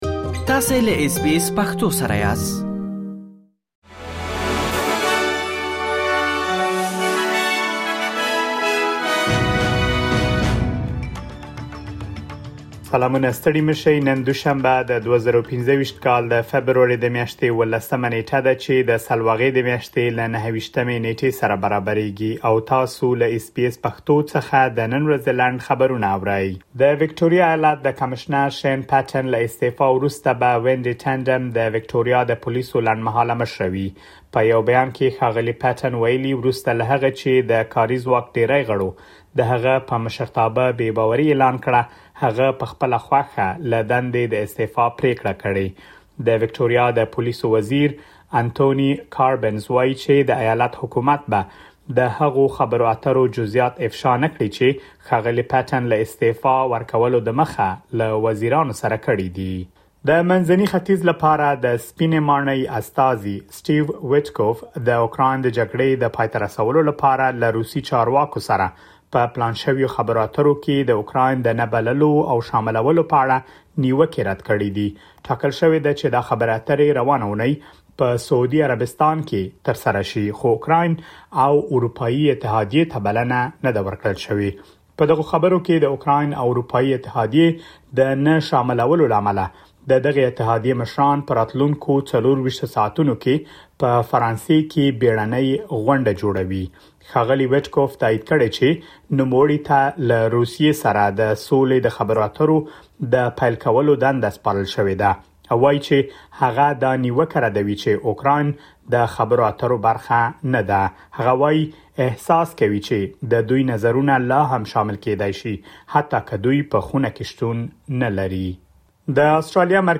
د اس بي اس پښتو د نن ورځې لنډ خبرونه | ۱۷ فبروري ۲۰۲۵